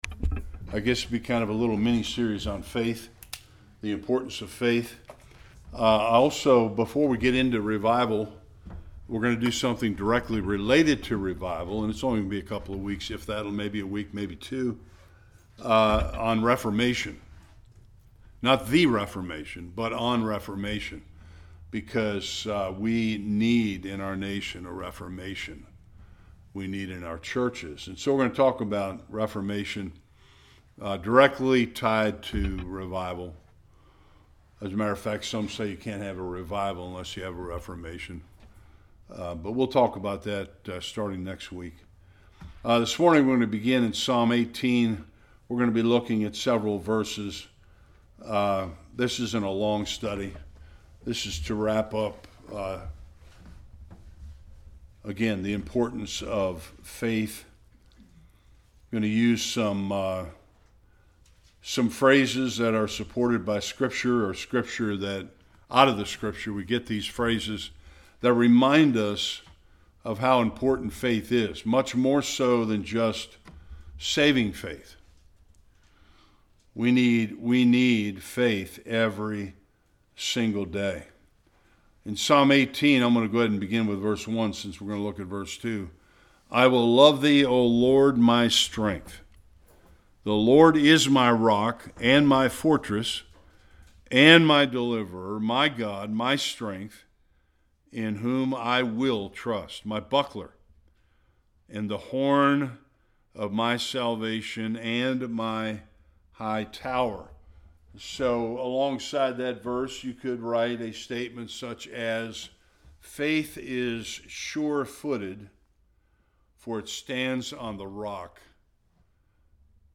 Various Passages Service Type: Bible Study Some passages that describe the importance of faith in the life of a Christian.